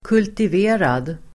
Uttal: [kultiv'e:rad]